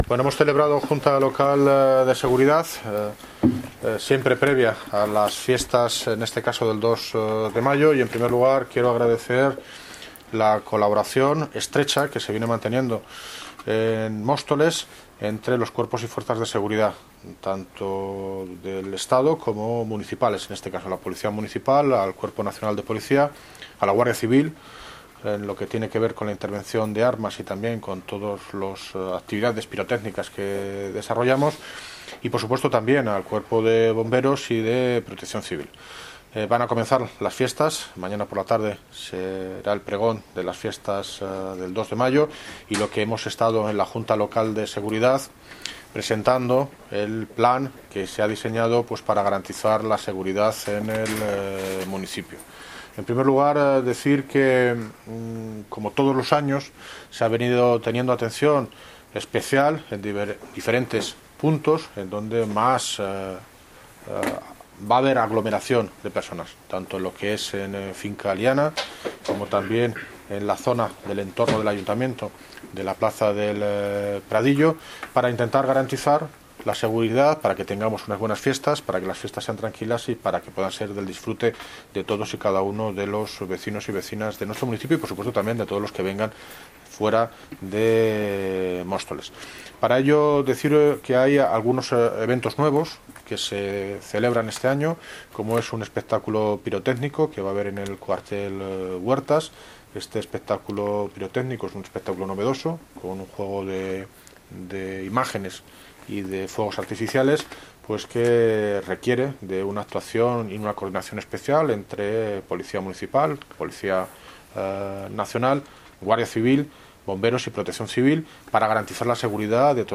Audio - David Lucas (Alcalde de Móstoles) Sobre Dispositivo de Seguridad en fiestas